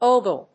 音節ogle 発音記号・読み方
/óʊgl(米国英語), ˈəʊgl(英国英語)/